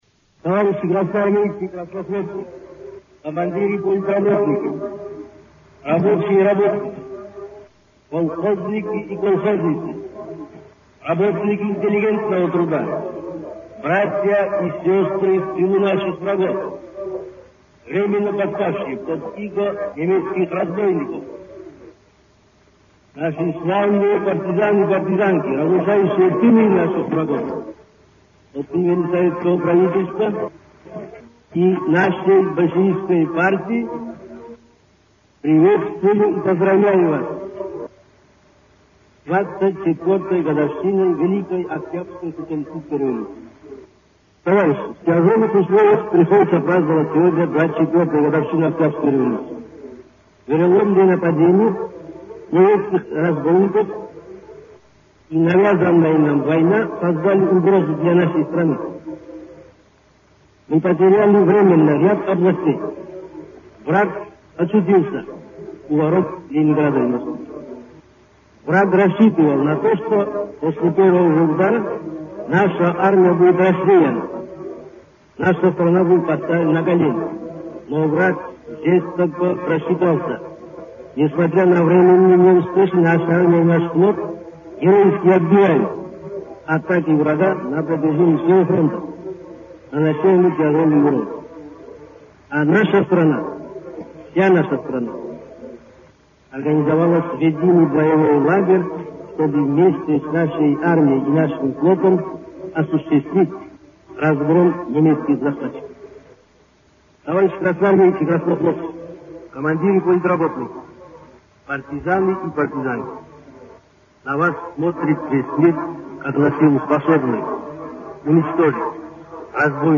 Речь тов.Сталина на параде 7 ноября 1941 г. на Красной площади в Москве